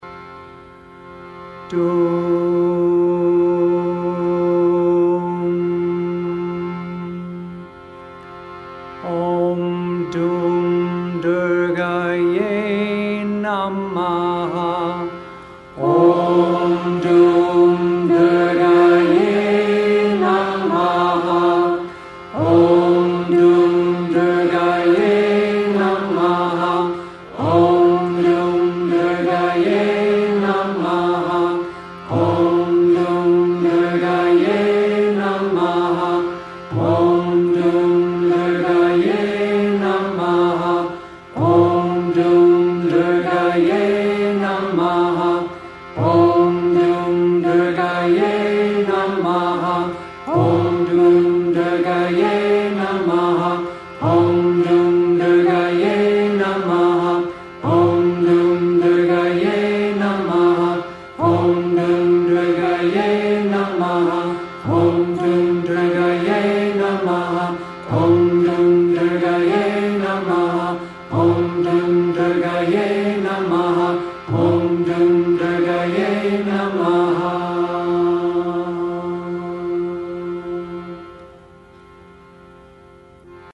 Durga, bija mantra
Durga-bija-mantra.mp3